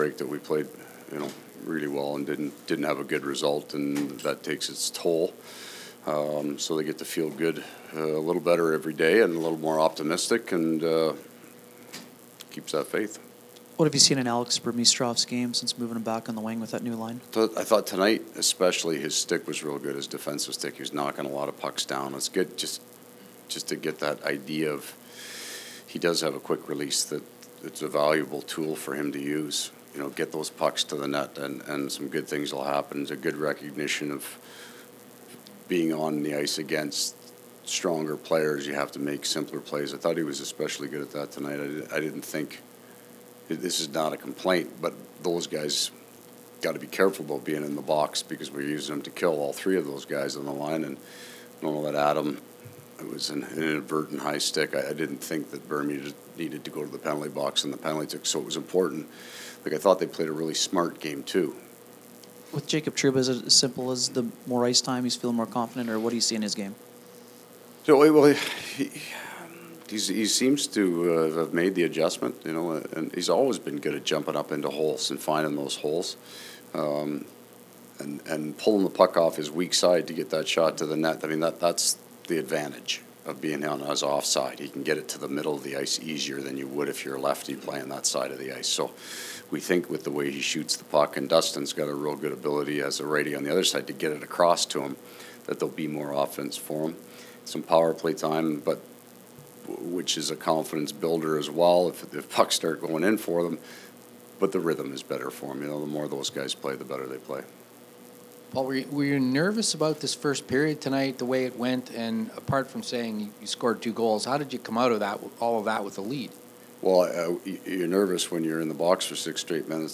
Coach post-game scrum
Winnipeg Jets coach Paul Maurice post-game press conference.